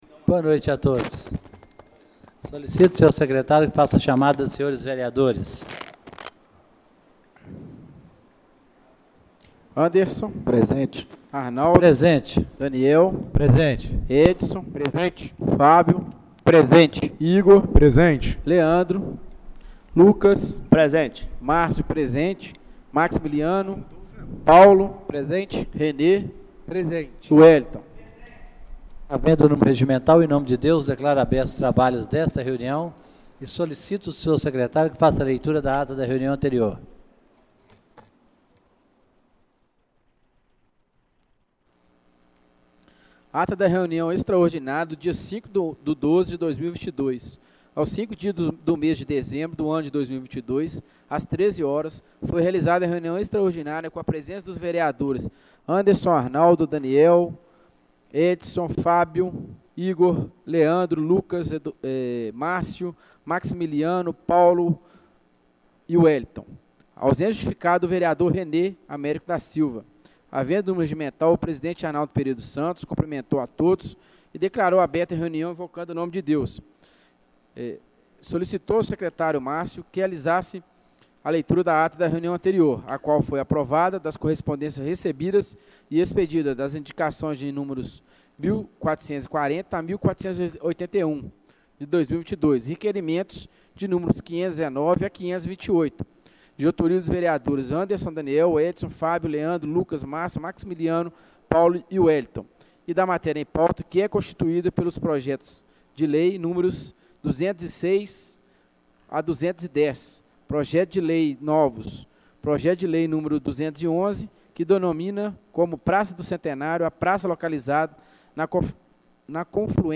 Reunião Ordinária do dia 12/12/2022